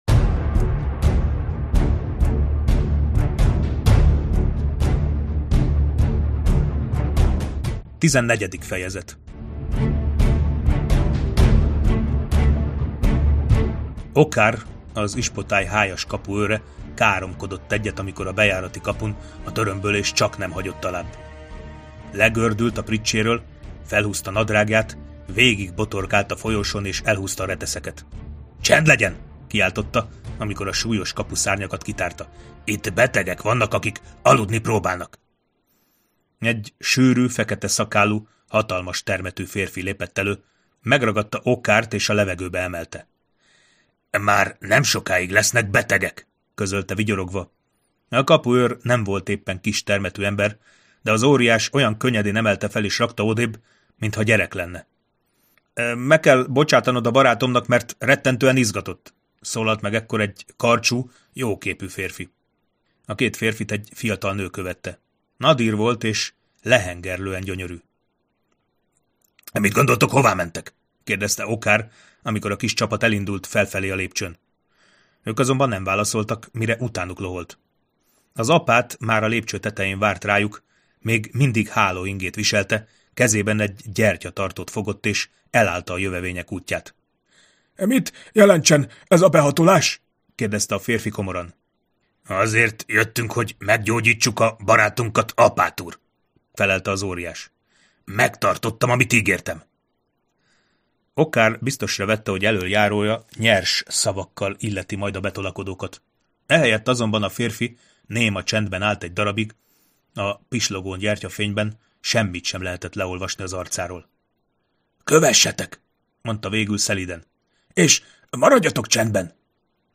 Hangoskönyv